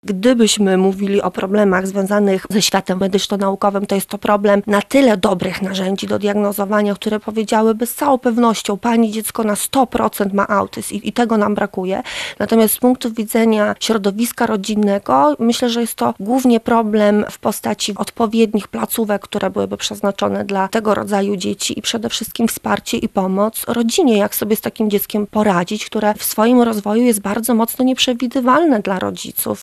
Konferencja odbywa się w Wyższej Szkole Ekonomii i Innowacji w Lublinie przy ul. Projektowej.